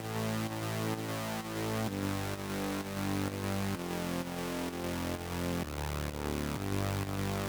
VDE 128BPM Notice Bass 2 Root A SC.wav